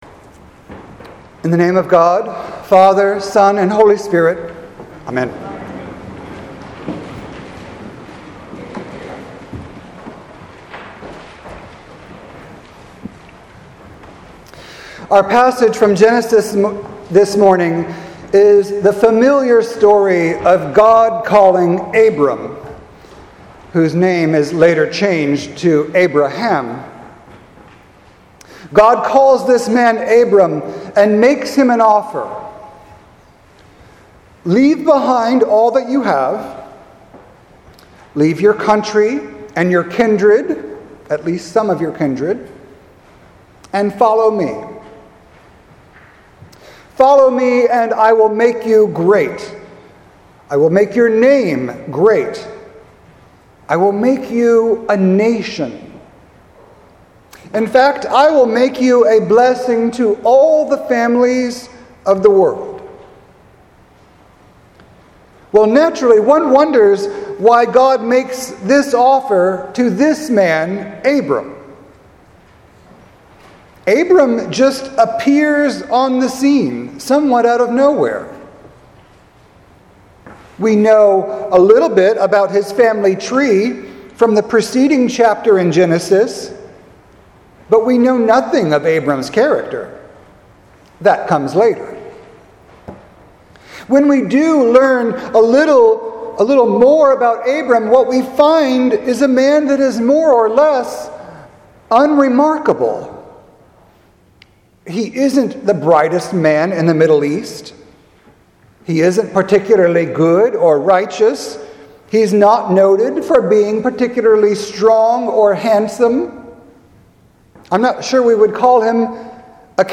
Sermon for March 8th, 2020
sermon-3-8-20.mp3